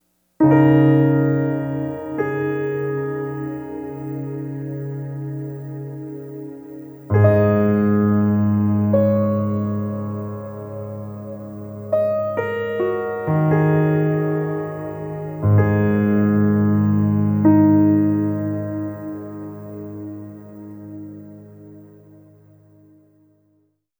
Reverb Piano 04.wav